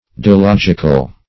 Dilogical \Di*log"ic*al\, a. Ambiguous; of double meaning.